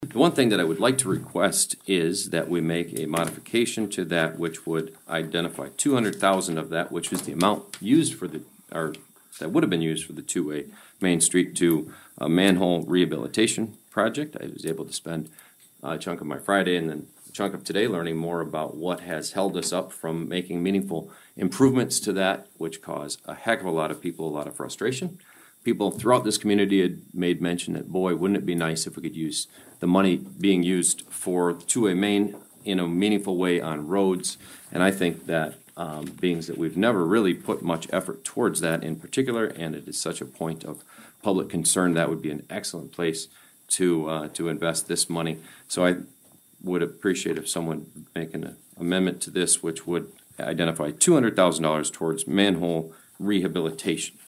ABERDEEN, S.D (Hub City Radio)- At the Aberdeen, SD City Council meeting earlier this Monday evening, October 6th, the City Council voted unanimously on an 8-0 vote to keep downtown Main Street as a one-way road.
Mayor Schaunaman suggested the $200,000 that was to be used for Main Street now could be used for manhole rehabilitation projects instead.